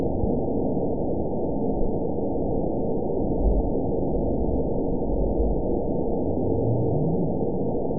event 911325 date 02/22/22 time 10:37:10 GMT (3 years, 2 months ago) score 9.43 location TSS-AB02 detected by nrw target species NRW annotations +NRW Spectrogram: Frequency (kHz) vs. Time (s) audio not available .wav